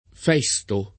Festo